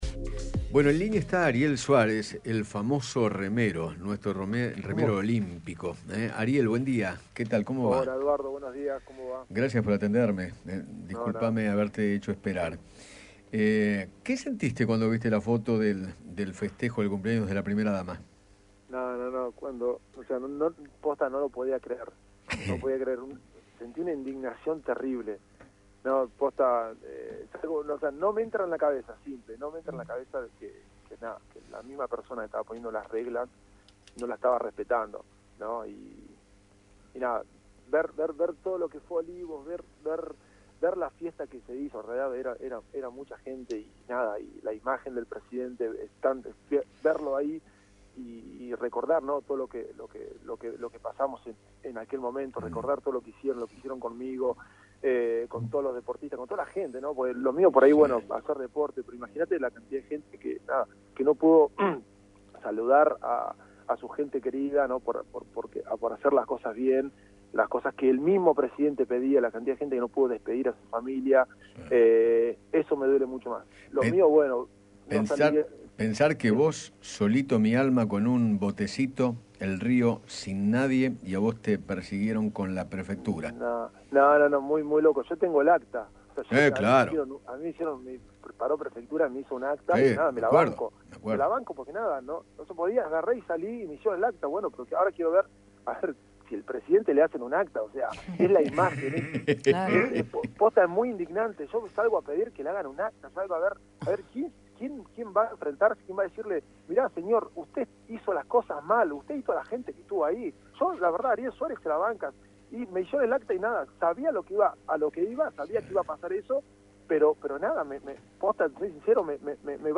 habló con Eduardo Feinmann sobre la polémica foto del festejo de cumpleaños de Fabiola Yáñez en Olivos durante el aislamiento obligatorio y expresó toda su bronca.